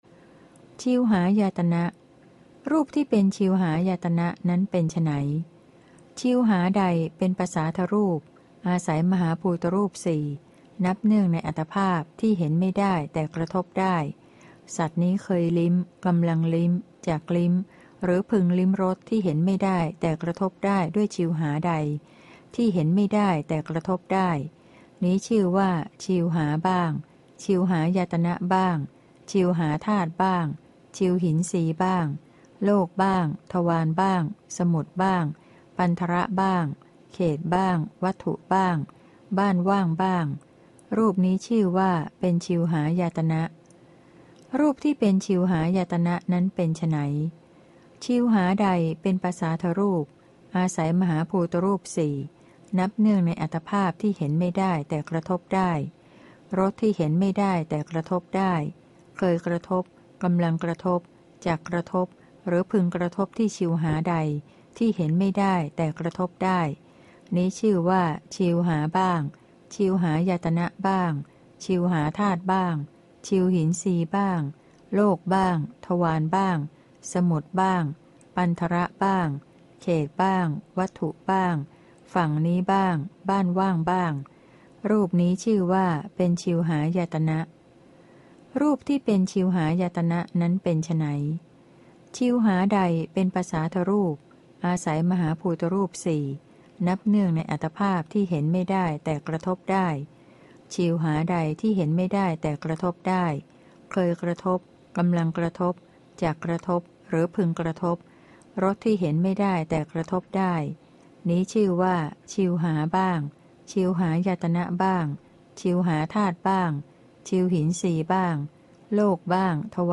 พระไตรปิฎก ภาคเสียงอ่าน ฉบับมหาจุฬาลงกรณราชวิทยาลัย - เล่มที่ ๓๔ พระอภิธรรมปิฏก